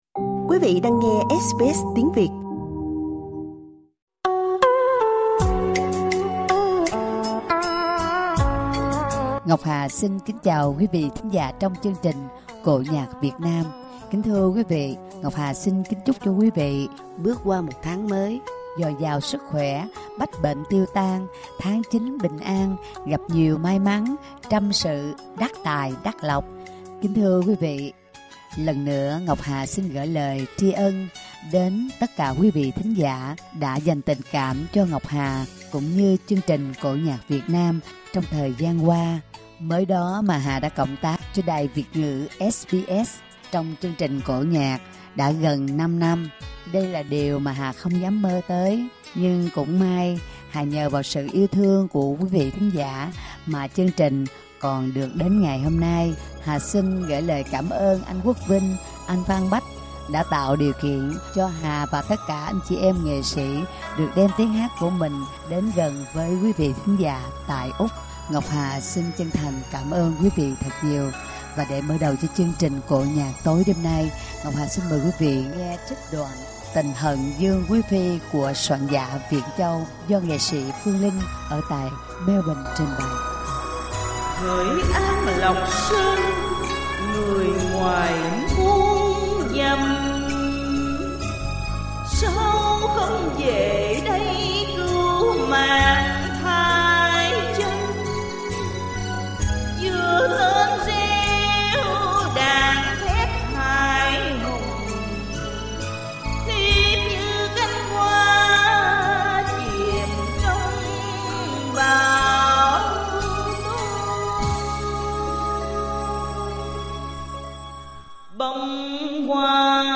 Xin mời quý vị nghe ca cảnh 'Tình hận Dương Quí Phi', bài Tân cổ